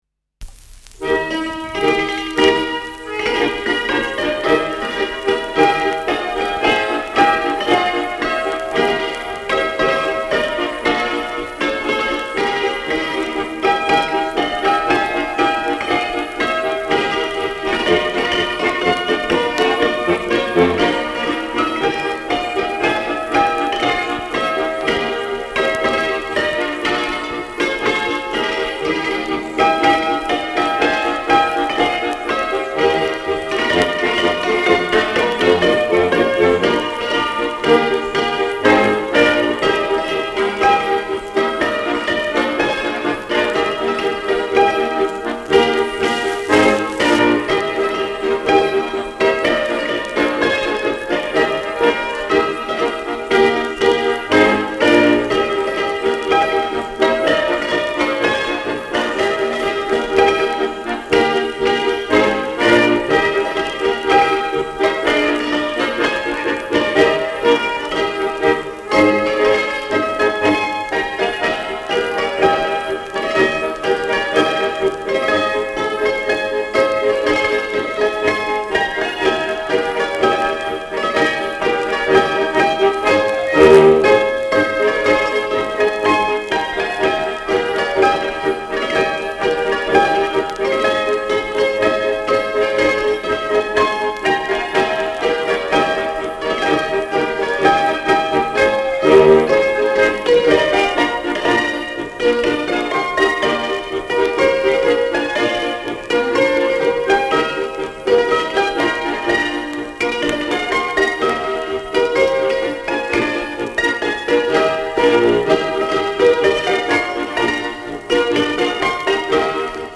Schellackplatte
Tonrille: Berieb : Kratzer und Schlieren 10-2 / 6 Uhr
gelegentliches Knistern
[Berlin] (Aufnahmeort)